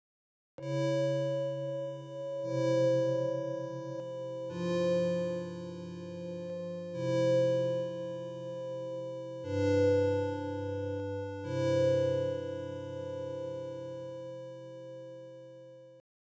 The chords combinations are named "T far" (c# minor, C major, F major and G major) and "T near" (C major, c minor, Ab major, Eb major).
The "T far" chord combination